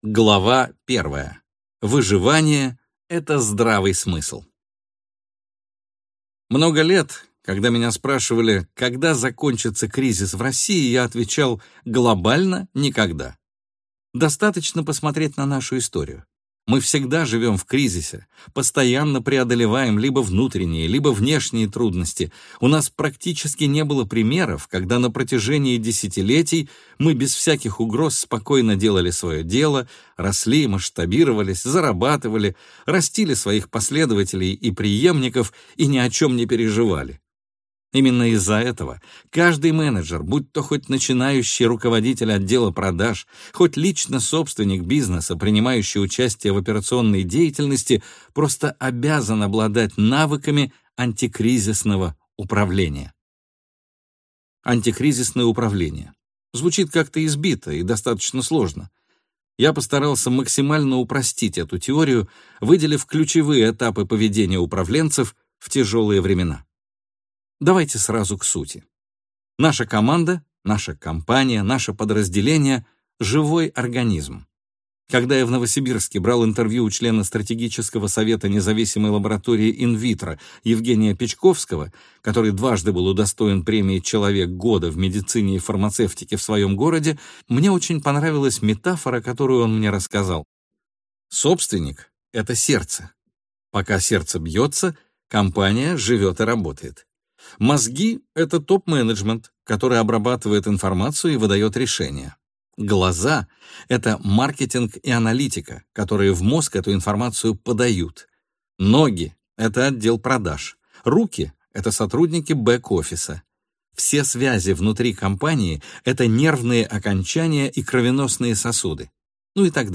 Аудиокнига Менеджмент во время шторма. 15 правил управления в кризис | Библиотека аудиокниг